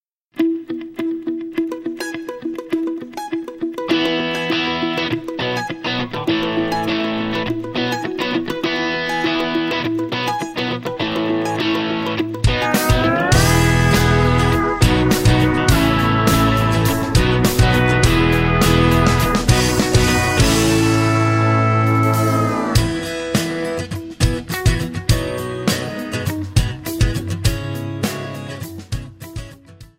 Backing track Karaoke
Country, 2000s